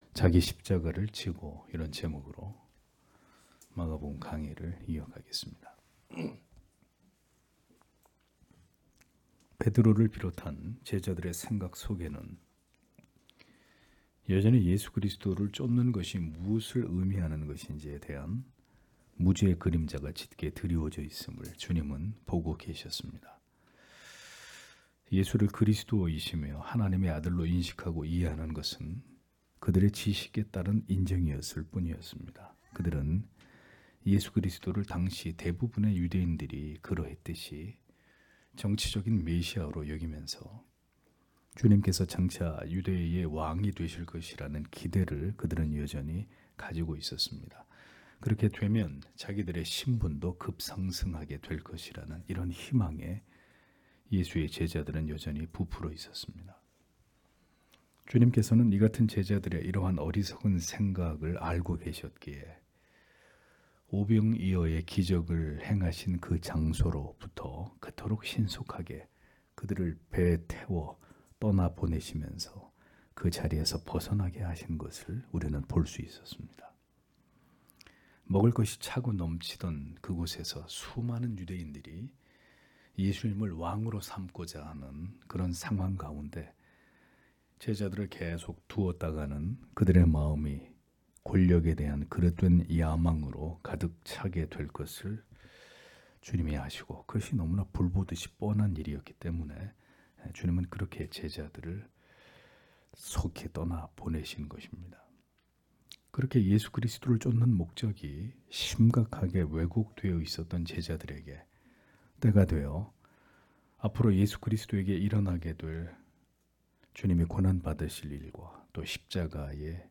주일오전예배 - [마가복음 강해 33] 자기 십자가를 지고 (막 8장 34-38절)